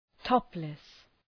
Shkrimi fonetik {‘tɒplıs}